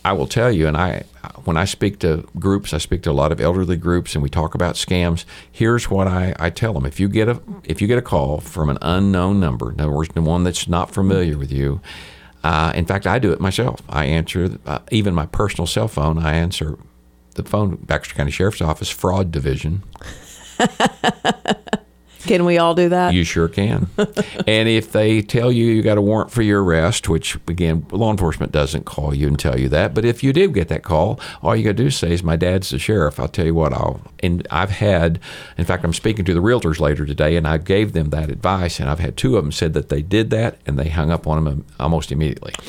In response to a surge in scams targeting Baxter County residents, KTLO hosted Sheriff John Montgomery Thursday morning for a live call-in program aimed at educating the public on how to avoid common scams.